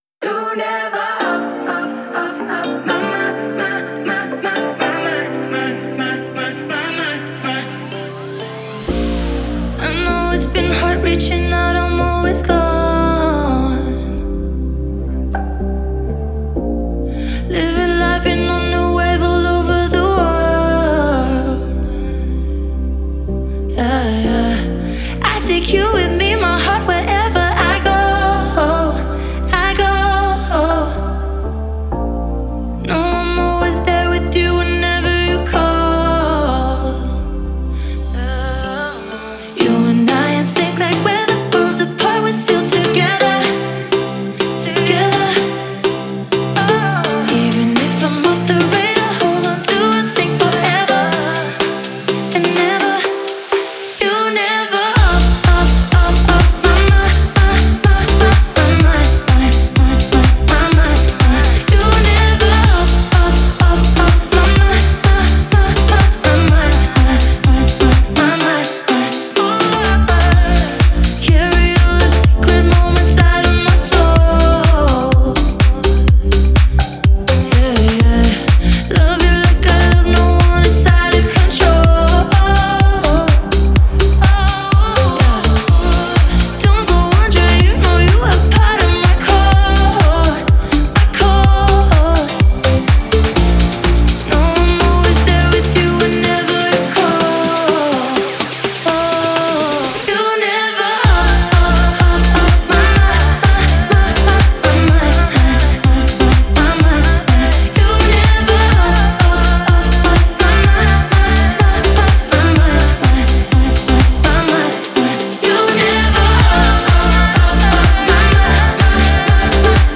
это захватывающая поп-песня